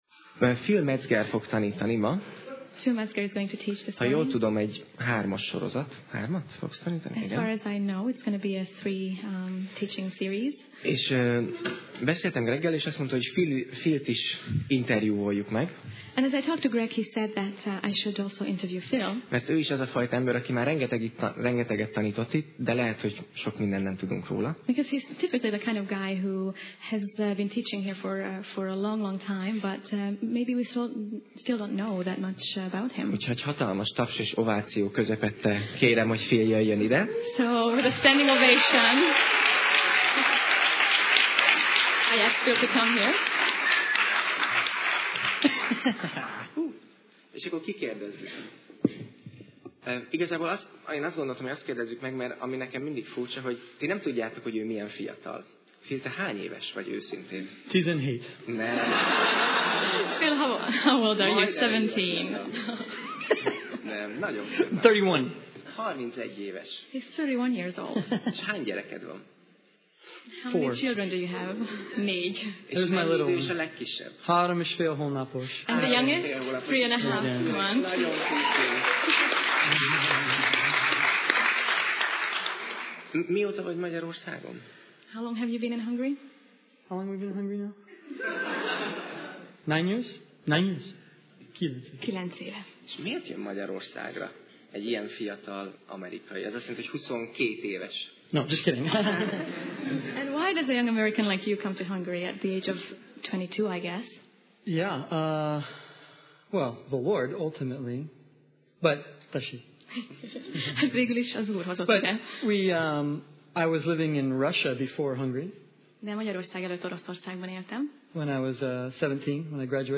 Tematikus tanítás Alkalom: Vasárnap Reggel